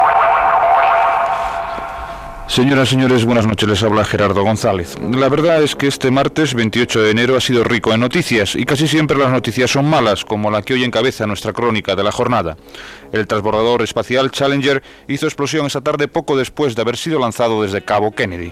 Careta del programa i titular.
Informatiu